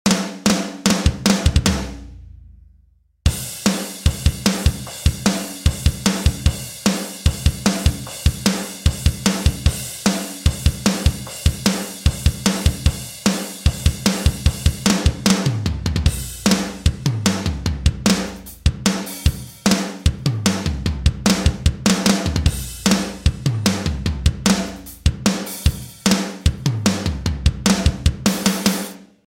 Powerful, studio-grade rock drums perfect for energetic, driving modern rock or metal. Captured with vintage mics and processed through analog gear for desert smack.
Snare Main (Drums)
Recorded at Fireside Sound in Joshua Tree, California during the making of Silverstein's albums Antibloom and Pink Moon.
dd-snare-main-drums.BtLwN7E0.mp3